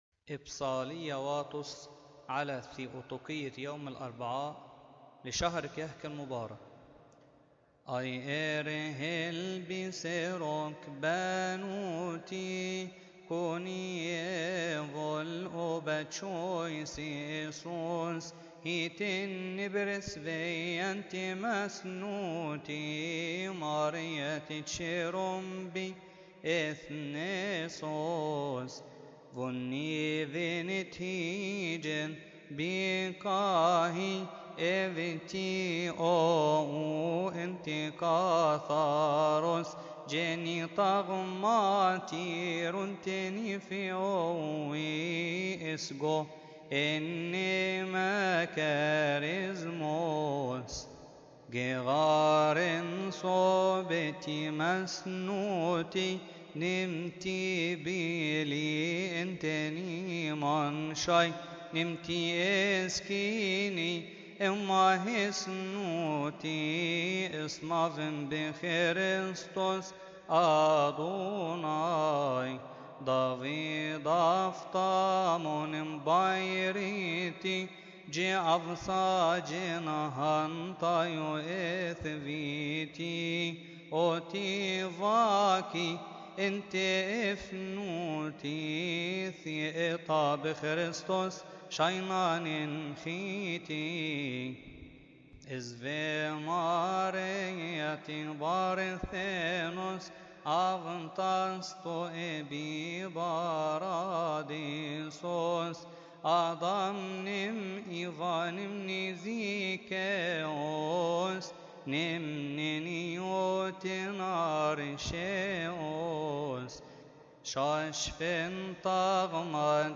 المرتل
ابصالية واطس تقال قبل ثيؤطكية الأربعاء تقال في تسبحة نصف الليل بشهر كيهك